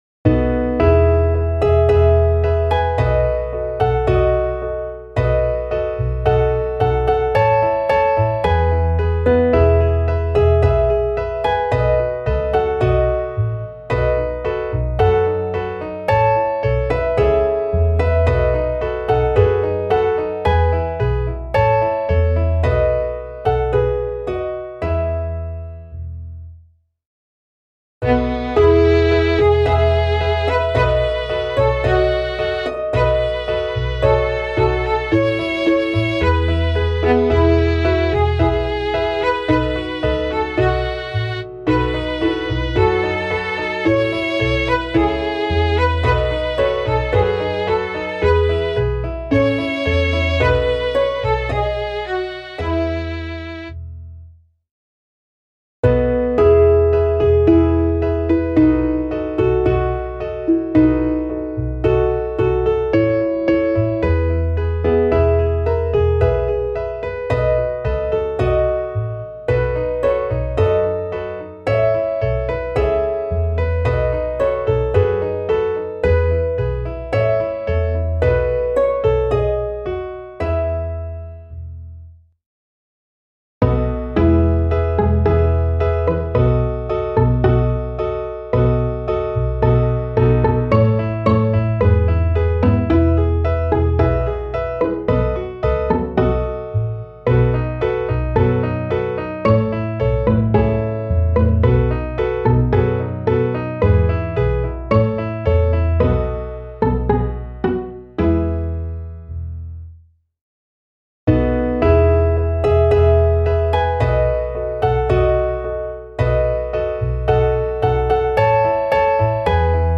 Klaviersatz
midi_der-gruene-wagen_klavier_320.mp3